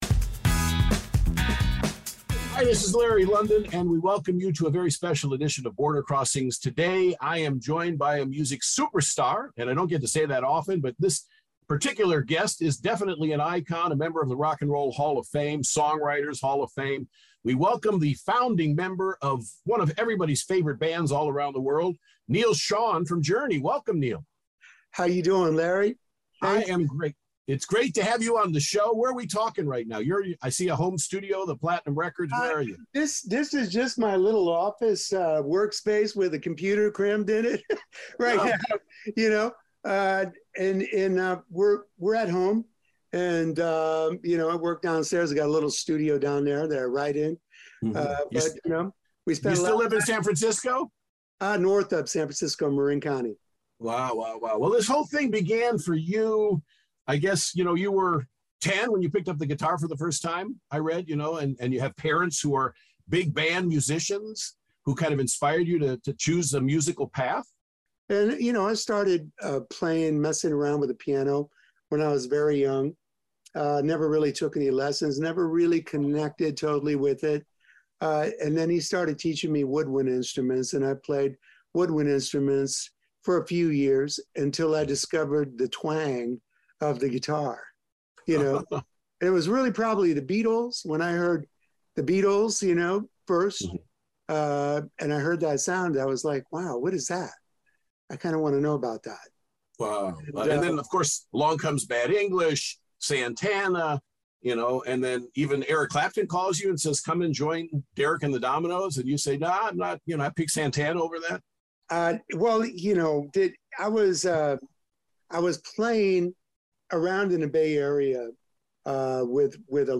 Founding member, guitarist, songwriter and producer Neal Schon of the band Journey is our guest this week on Border Crossings. Journey has released their first studio album of all news songs in over a decade “Freedom.”